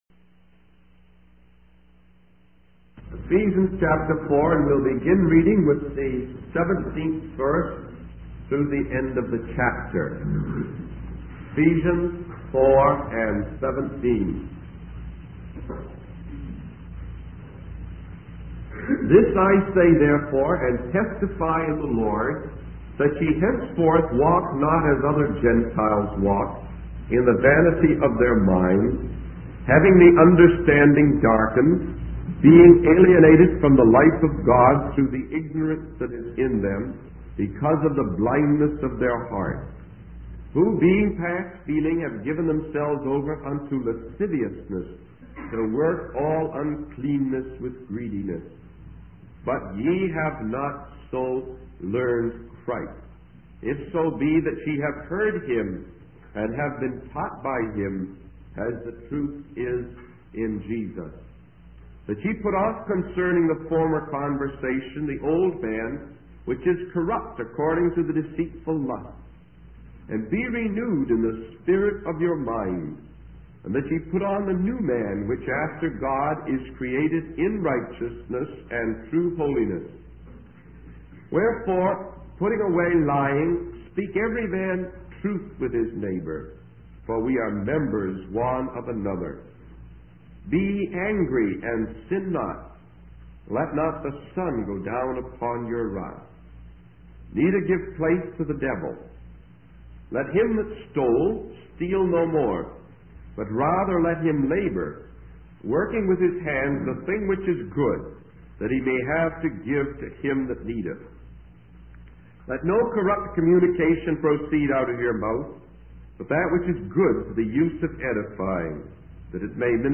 In this sermon, the speaker begins by addressing the need for preparation for conflict. He emphasizes the importance of relying on the strength and power of the Lord, rather than human might.